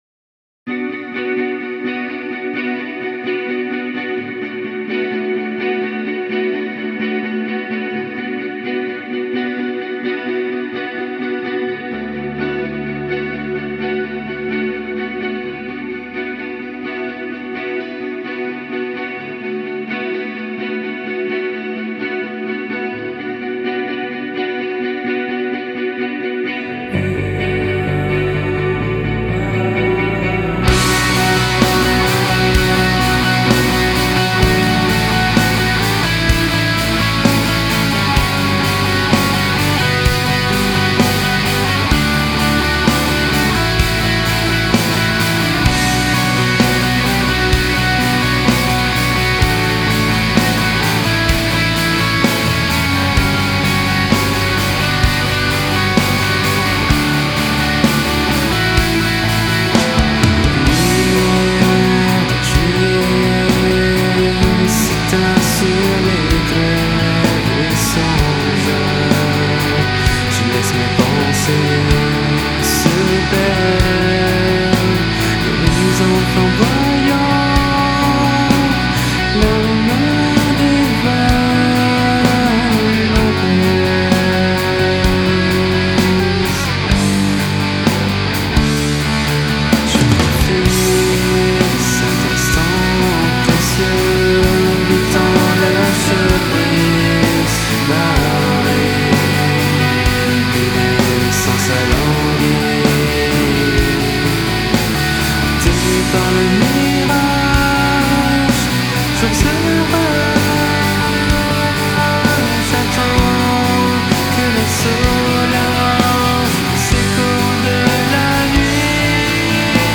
Post metal Shoegaze black gaze